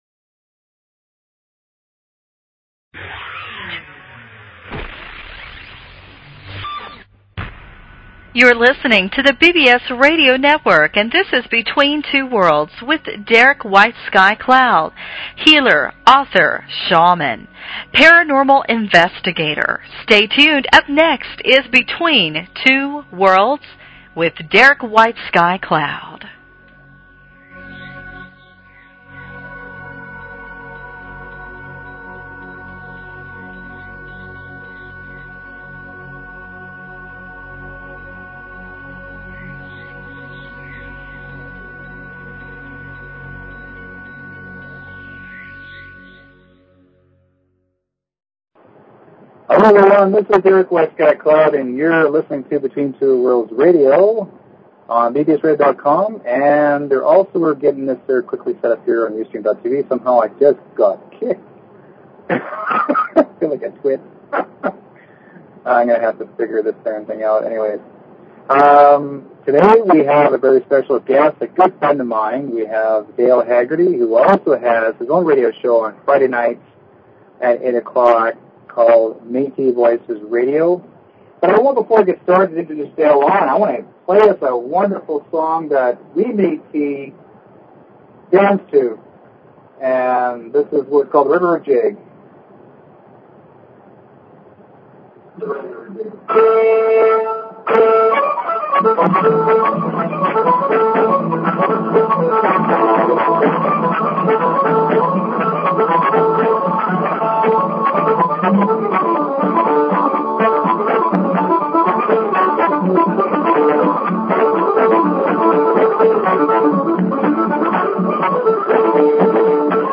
Talk Show Episode, Audio Podcast, Between_Two_Worlds and Courtesy of BBS Radio on , show guests , about , categorized as
This will be a fun show with a flair of music and laughter & possibly a little politics. Who are the Metis people?